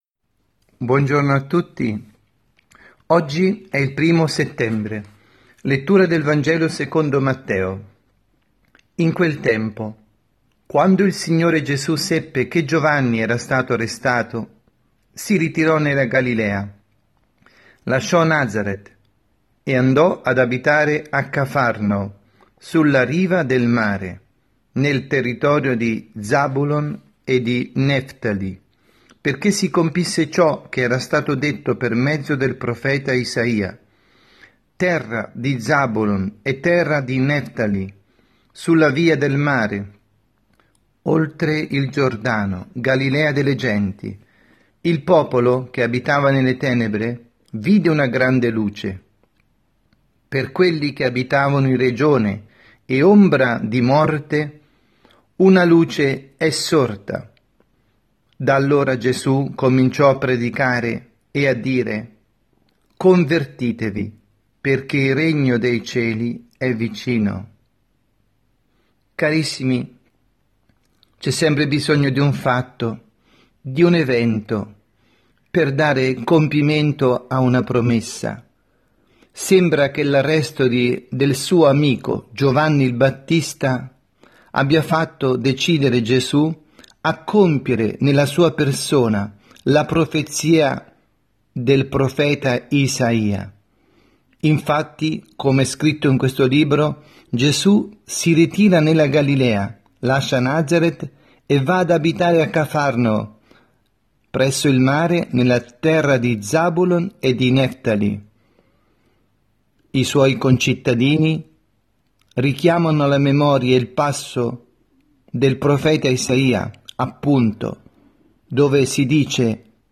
2019-09-01_Domenica_pMG_Omelia_dalla_Parrocchia_S_Rita_Milano_ore_10_00